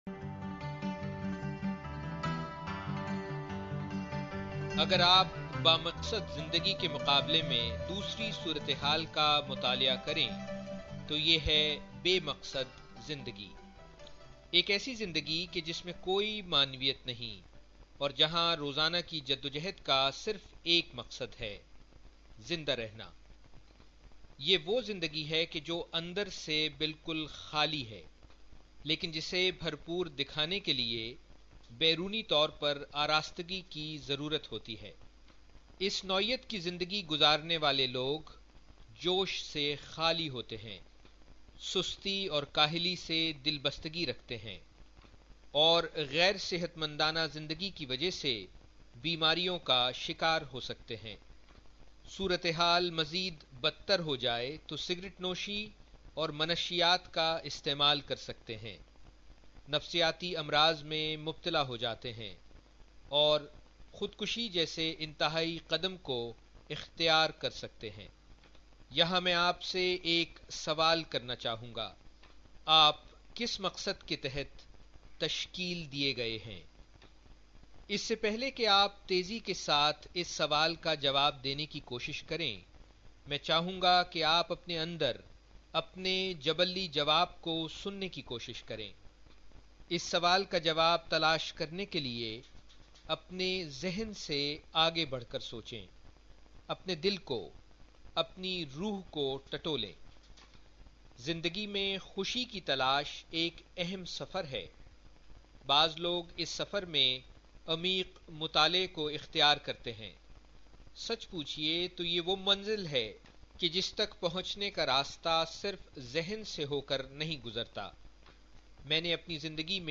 An outstanding Urdu Podcast of a Leadership Workshop, the first of its kind. It takes its audience to the journey of self exploration while they learn to write their Mission Statement.